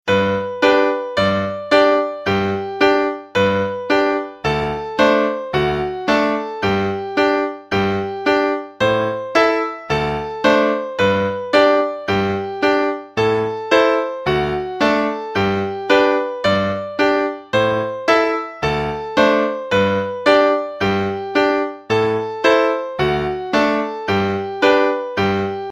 Accordeon diatonique et Musiques Traditionnelles
Un autre 2/4 "Carré de l'Est" avec un accord nouveau la mineur en tiré à la main gauche
En deuxième phrase, main gauche commencer sur un accord de La mineur en tiré